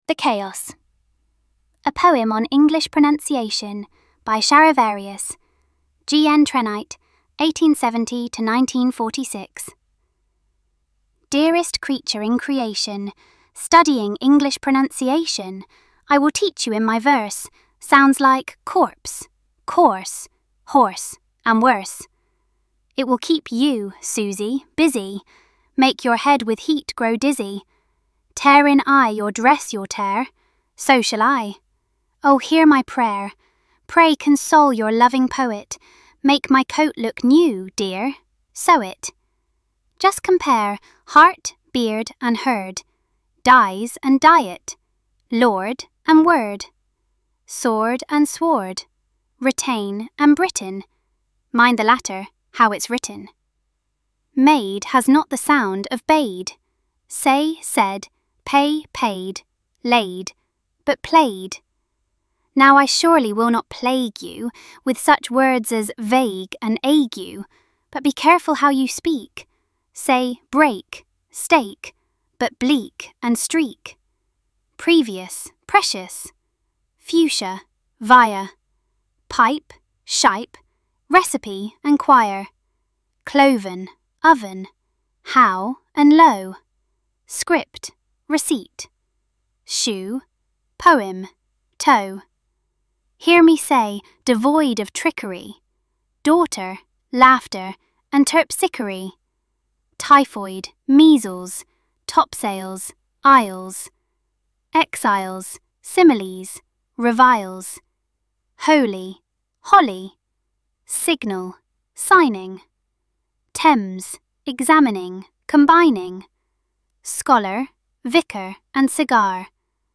Compare that with the version of GNU Speech released in 1995. It still messes up "tear" and "live". But once you get past the unnatural voice, it's far more precise.